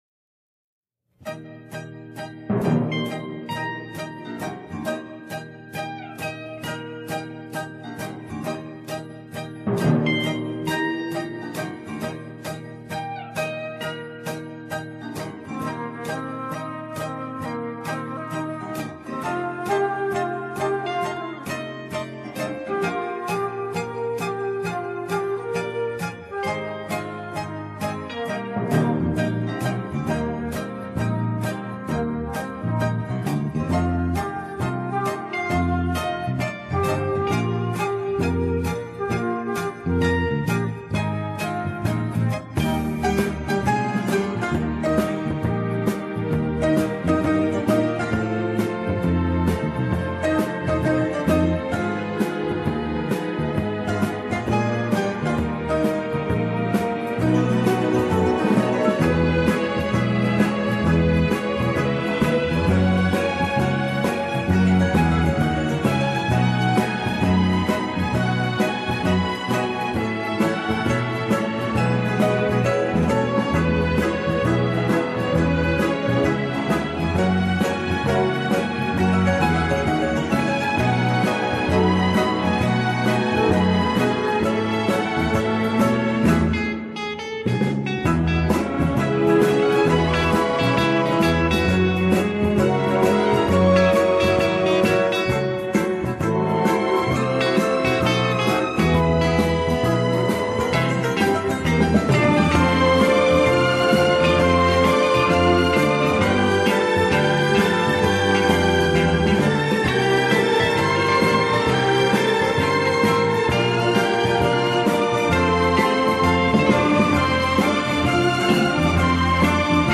Genre:Funk / Soul, Jazz, Rock